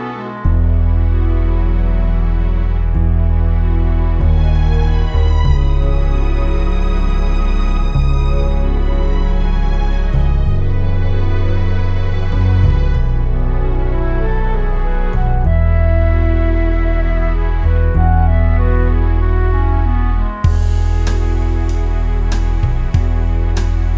No Whistle Solo Easy Listening 3:56 Buy £1.50